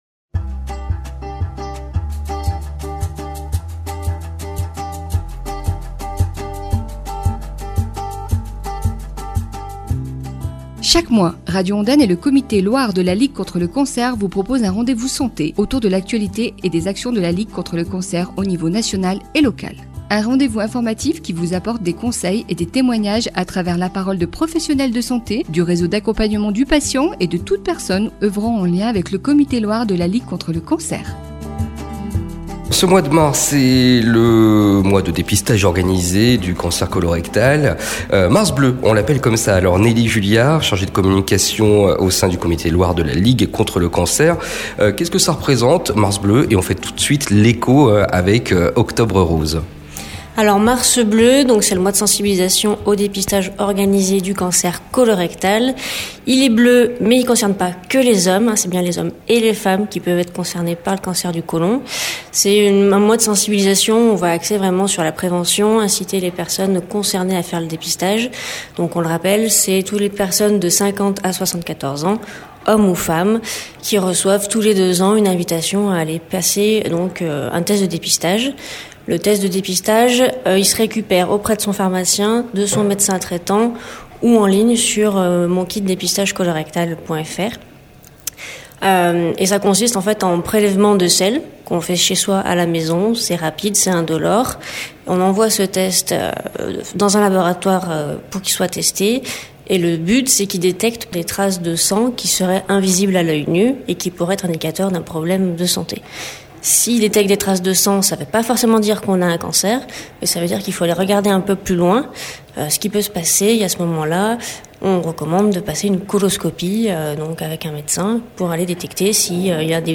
une émission sur Mars Bleu, mois de sensibilisation au dépistage organisé du Cancer du Colorectal, avec notamment le Côlon Tour du 31 Mars à la Ricamarie, et la 11ème édition des Foulées de la Ligue, à Saint-Galmier le 30 Mars